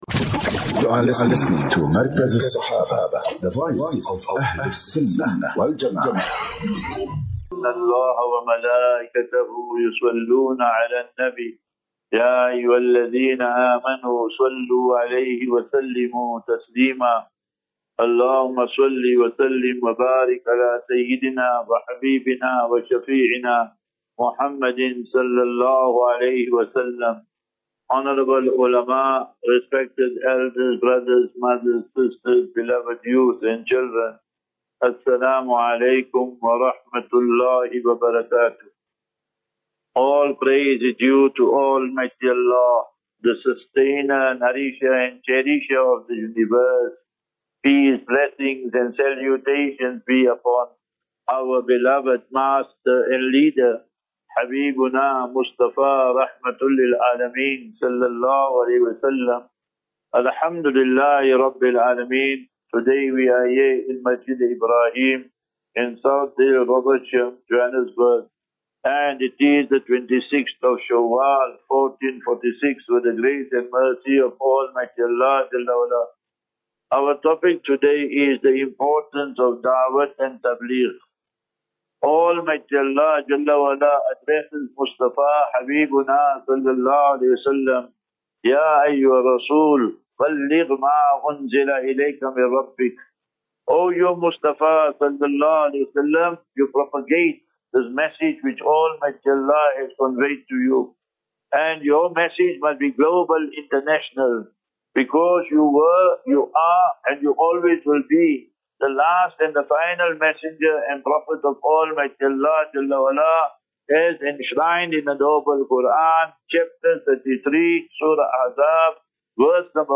25 Apr 25 April 2025 - Jumu'ah Lecture MASJID EBRAHIM - ROBERTSHAM